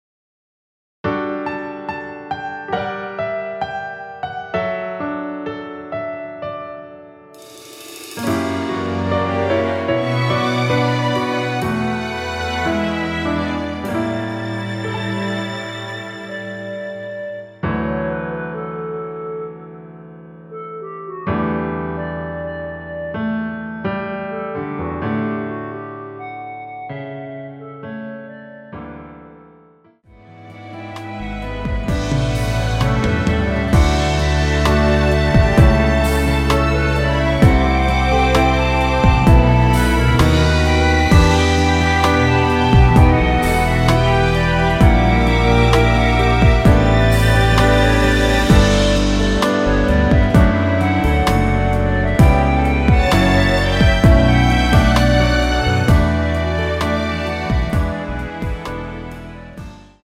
원키 멜로디 포함된 MR 입니다.
D
앞부분30초, 뒷부분30초씩 편집해서 올려 드리고 있습니다.
중간에 음이 끈어지고 다시 나오는 이유는